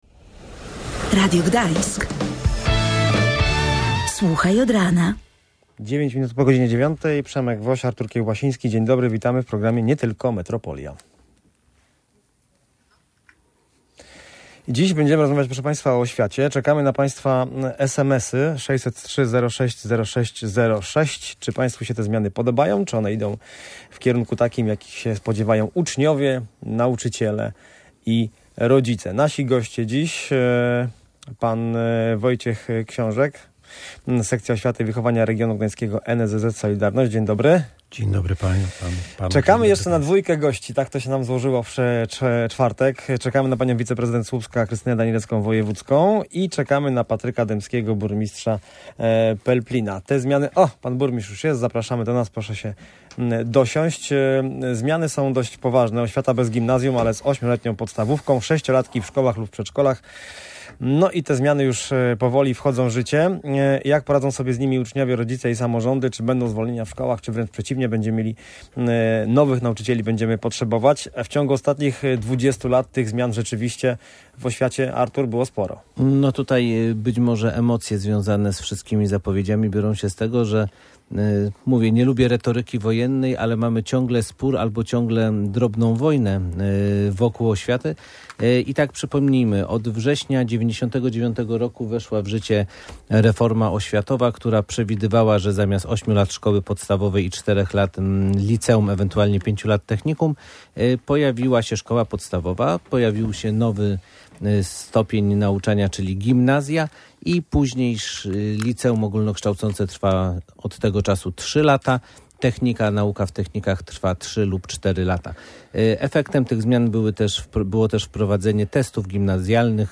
Czy reformy systemu edukacji są potrzebne, czy może komplikują proces nauczania? To pytanie było tematem przewodnim audycji Nie Tylko Metropolia.